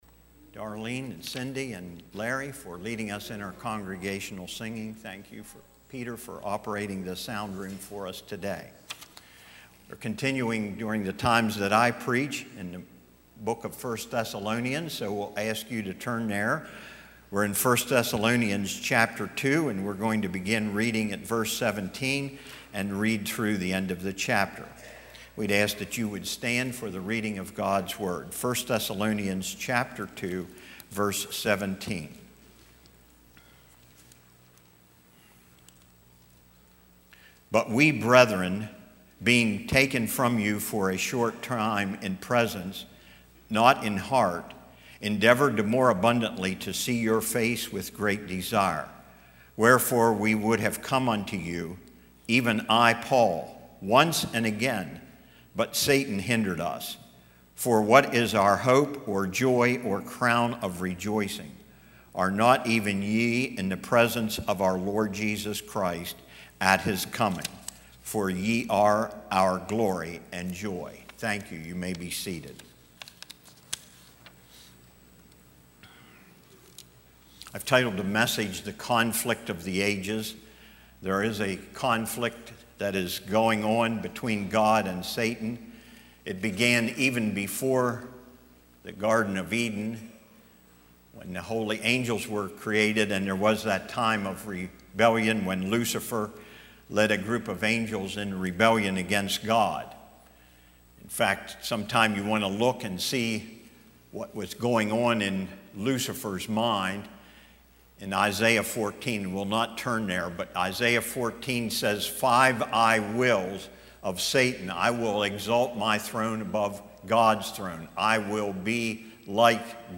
1 Thessalonians 2:17-20 Service Type: Sunday 9:30AM I. Satan’s Corruption II.